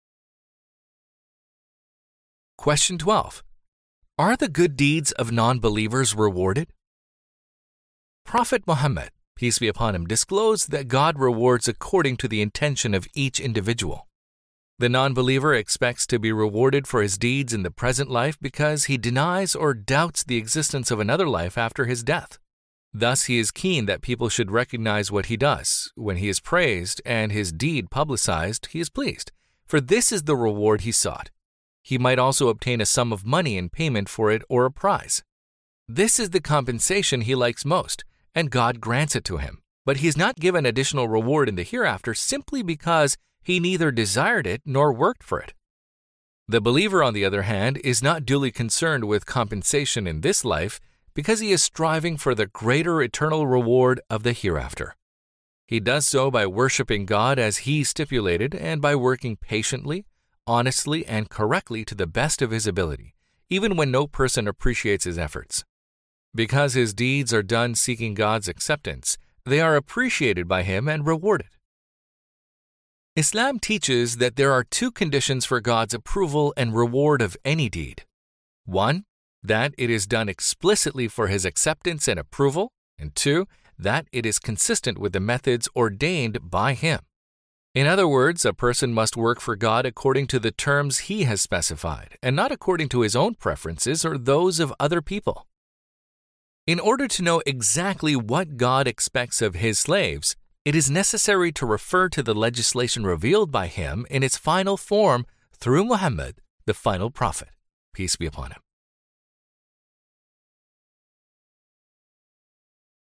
lectures
Does Allaah Reward Non-Muslims for Good Deeds？ (Islamic Audiobook) Clear Your Doubts About Islam.mp3